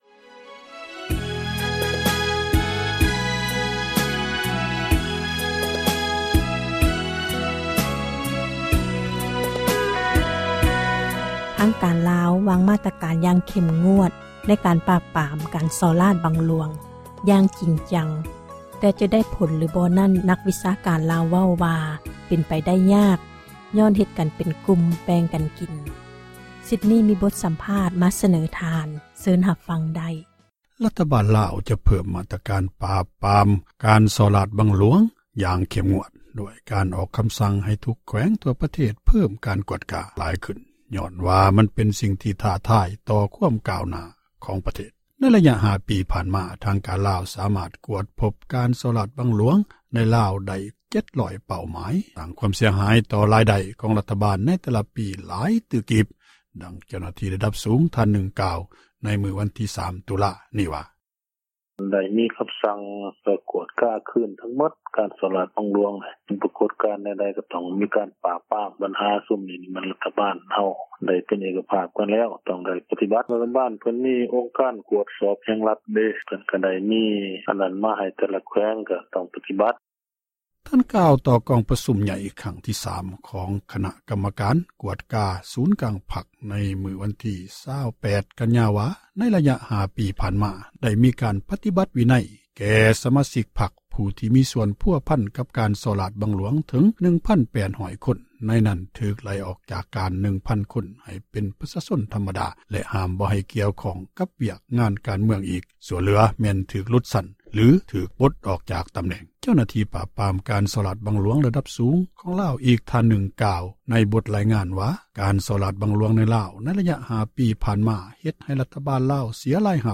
ການປາບກຸ່ມສໍ້ໂກງຂອງ ທາງການ — ຂ່າວລາວ ວິທຍຸເອເຊັຽເສຣີ ພາສາລາວ